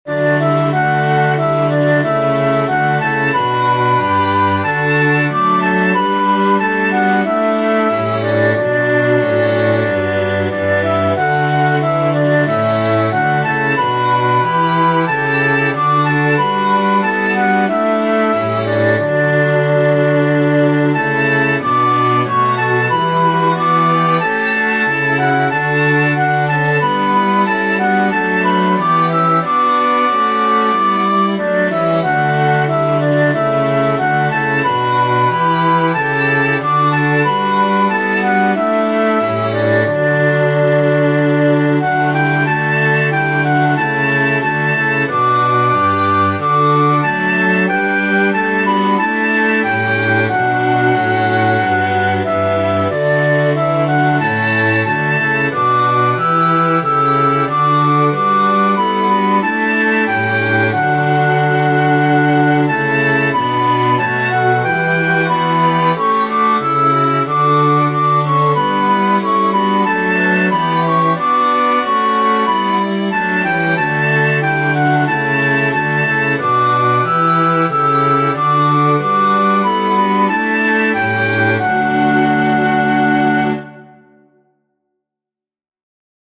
Mixed Woodwind & String Ensembles
Flute,Clarinet,Violin,Viola,Cello,(Oboe)